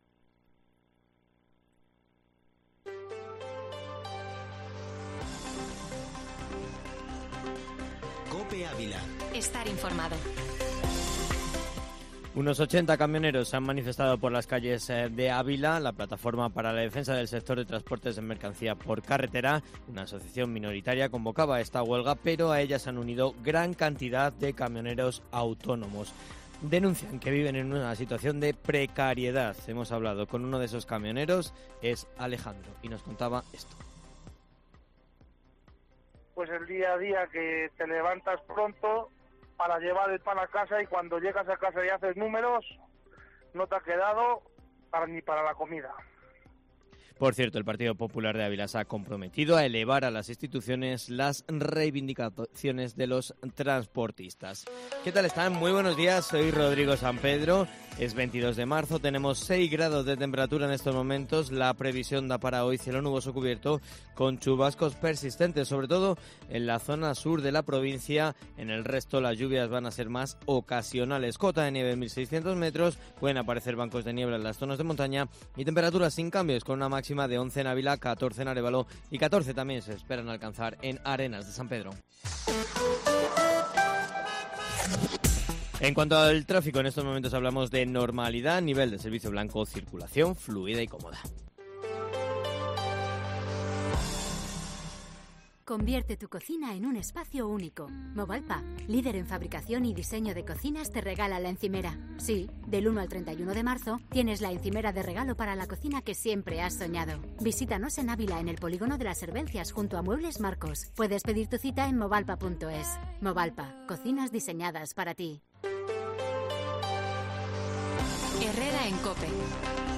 Informativo Matinal Herrera en COPE Ávila -22 Marzo